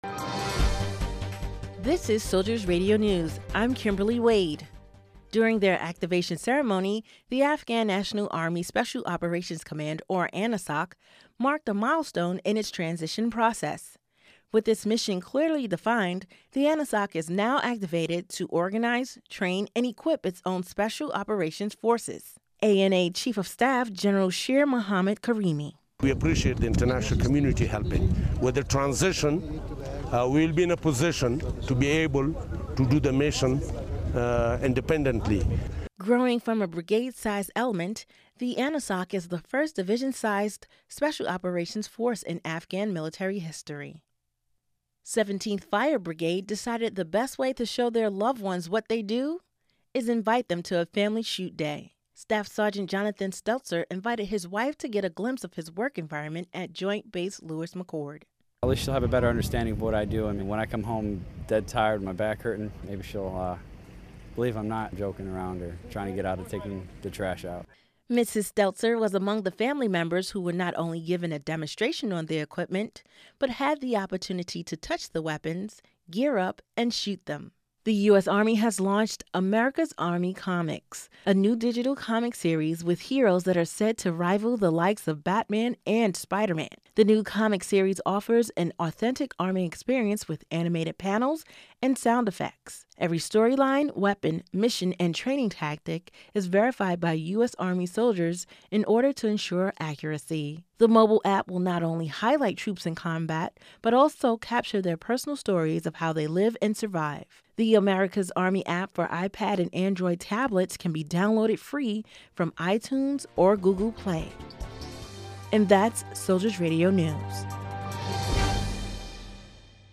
Soldiers Radio News - July 19, 2012